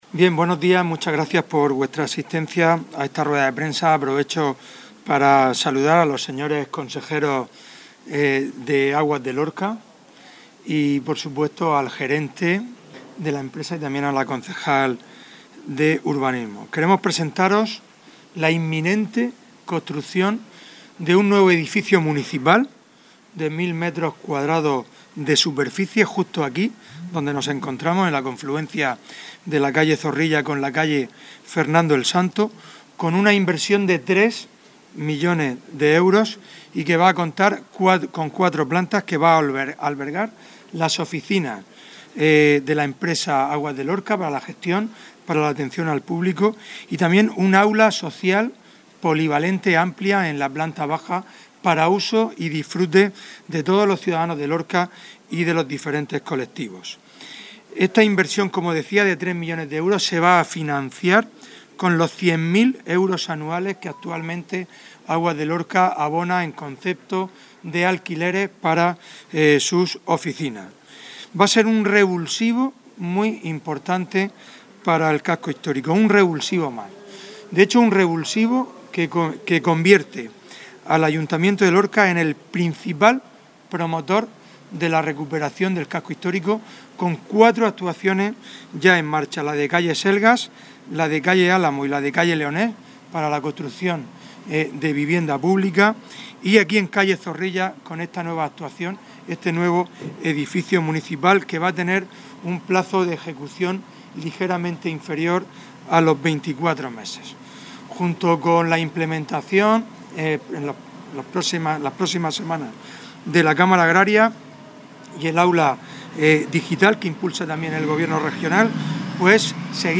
El alcalde de Lorca, Fulgencio Gil, ha informado de una nueva inversión cercana a los tres millones de euros que va a permitir la regeneración de un espacio urbano ubicado entre las calles Zorrilla y Fernando El Santo, en pleno corazón del casco histórico, que va a posibilitar la construcción de un nuevo edificio municipal de 1.000 metros cuadros de superficie total. Escuchamos la intervención de Gil Jódar.